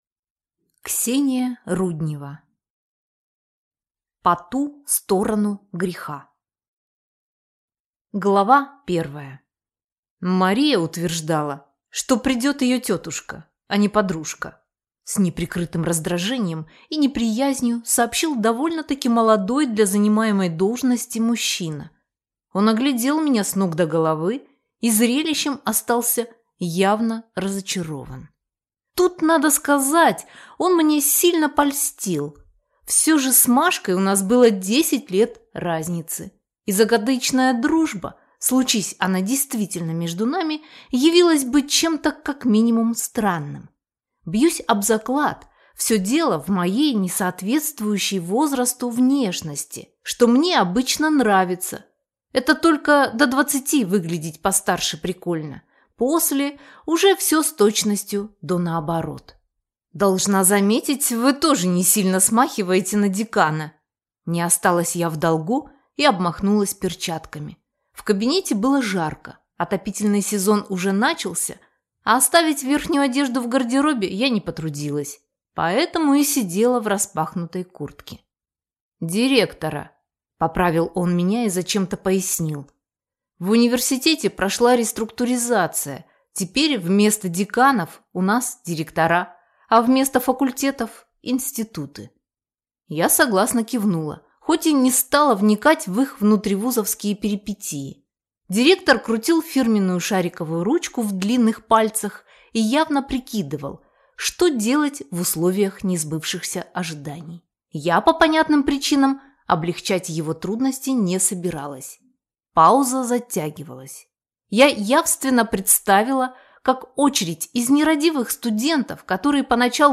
Аудиокнига По ту сторону греха | Библиотека аудиокниг